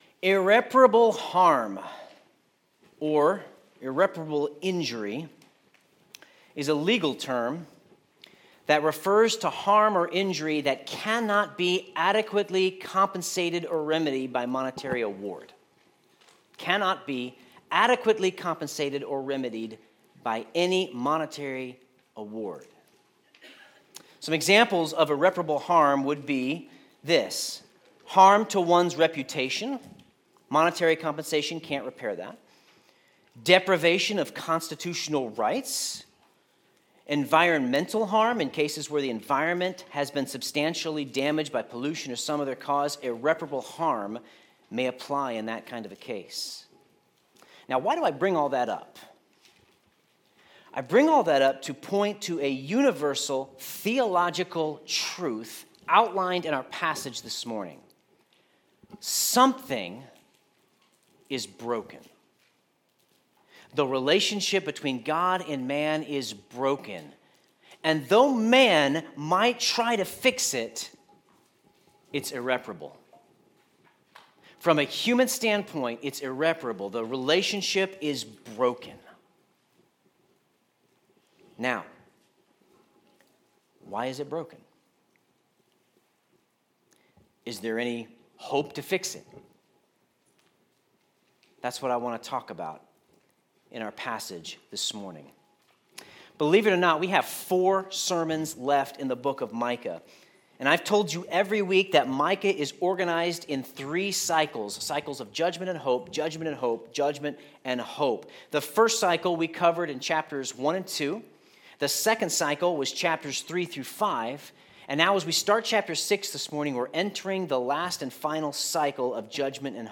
Sermon Notes Our relationship with God is broken.